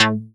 synTTE55006shortsyn-A.wav